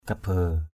kabe.mp3